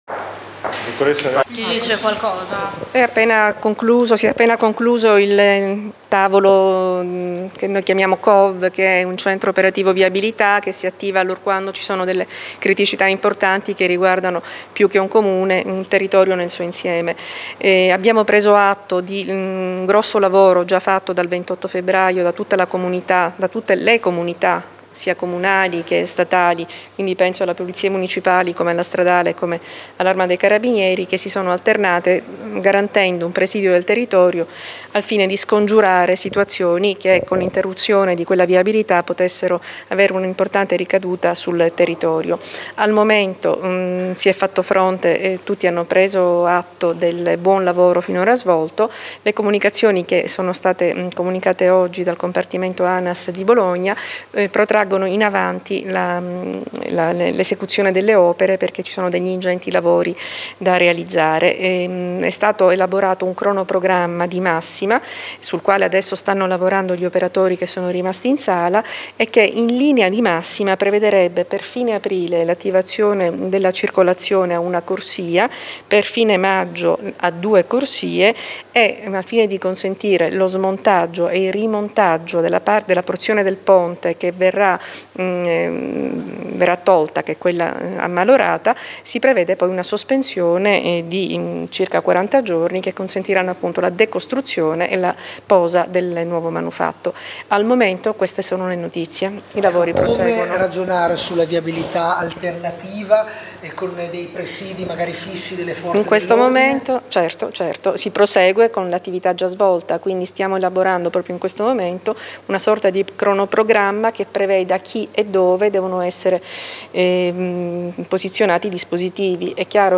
La prefetta di Modena Fabrizia Triolo: